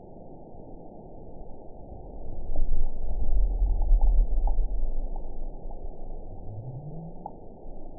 event 912274 date 03/23/22 time 12:30:15 GMT (3 years, 1 month ago) score 9.62 location TSS-AB03 detected by nrw target species NRW annotations +NRW Spectrogram: Frequency (kHz) vs. Time (s) audio not available .wav